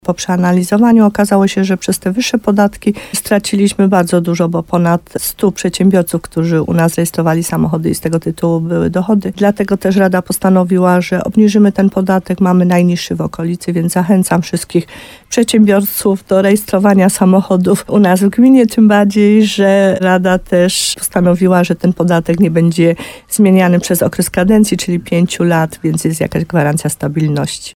Jak mówi wójt Małgorzata Gromala, chodzi o to, żeby do gminy wrócili przedsiębiorcy, którzy w ostatnich latach przenieśli się do innych miejscowości.